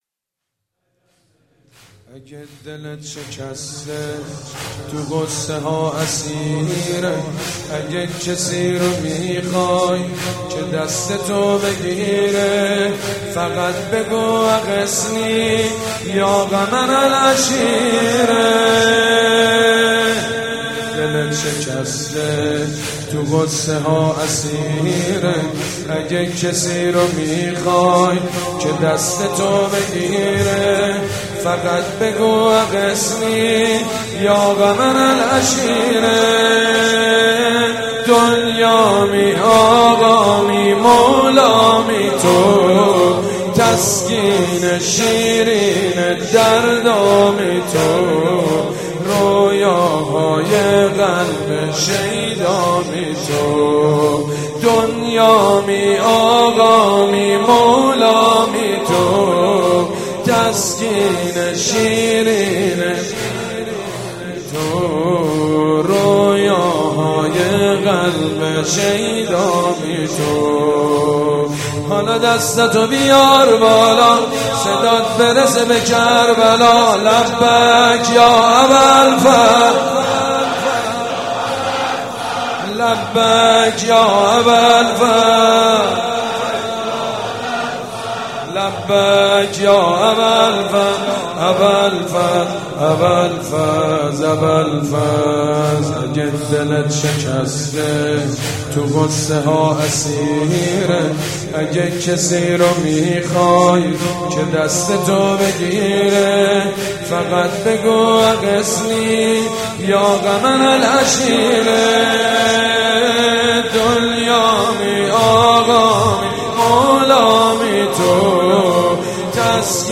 مداح
مراسم عزاداری شب شام غریبان